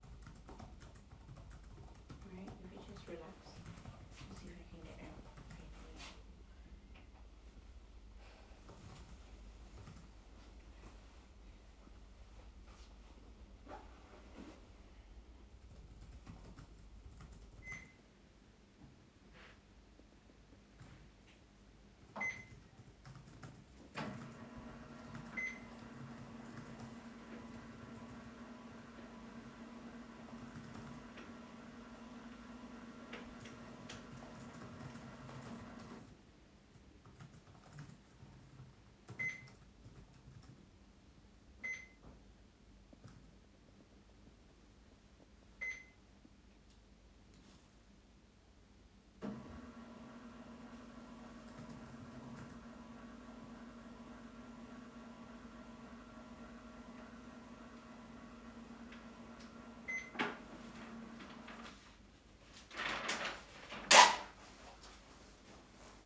Threads of noise, tape loops, and samples.